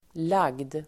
Uttal: [lag:d]